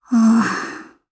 Gremory-Vox_Sigh_jp.wav